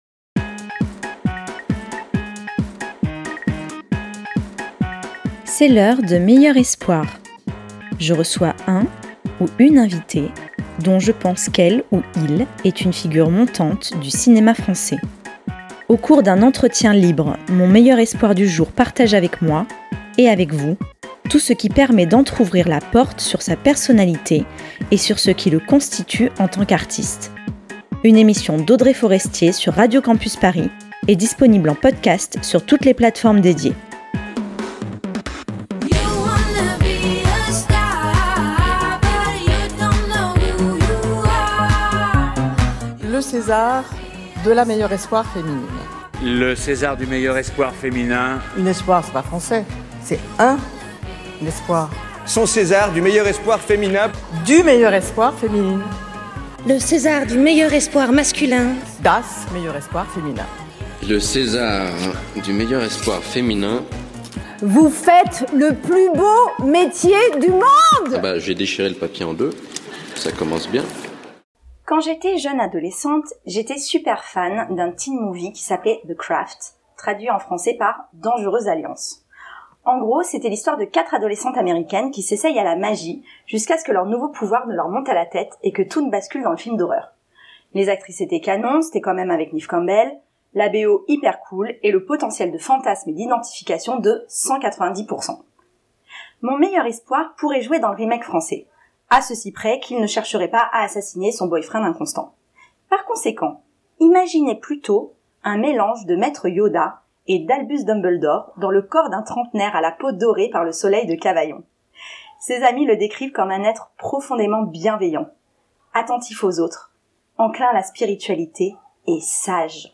Entretien